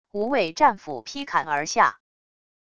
无畏战斧劈砍而下wav音频